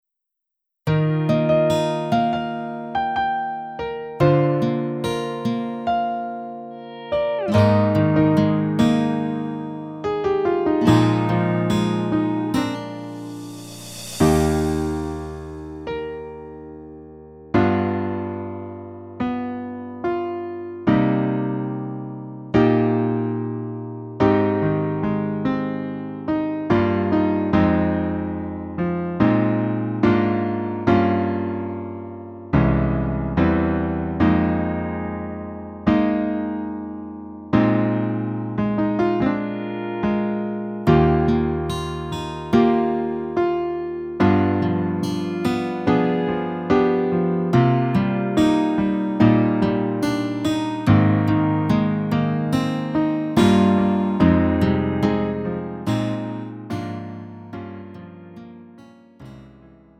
음정 원키
장르 가요 구분 Lite MR